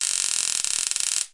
电器
描述：电力
标签： 电气 电力 休克 震惊 电力
声道立体声